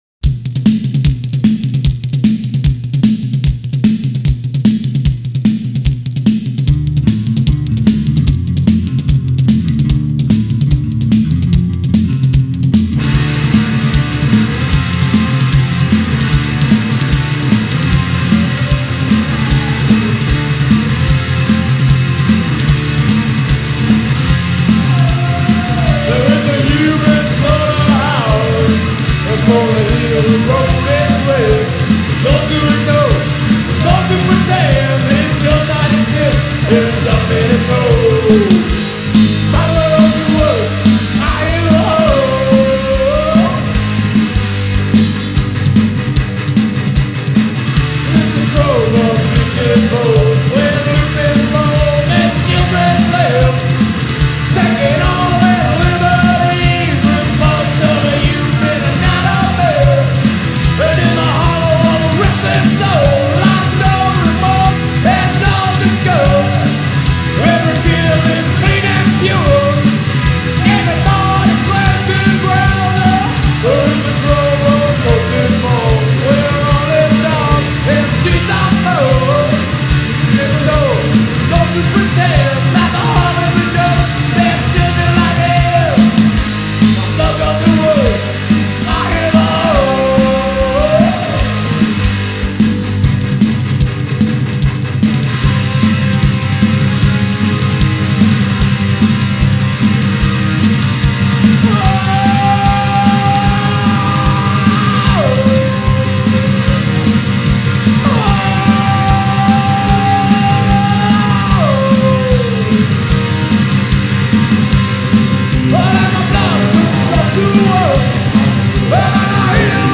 Album Reviews, Genre, Hard Rock